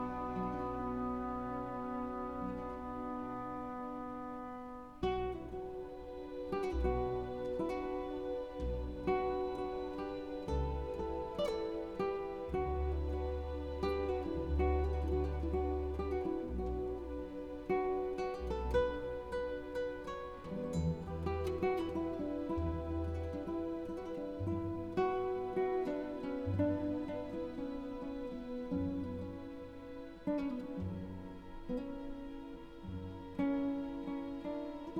Classical Guitar Orchestral
Жанр: Классика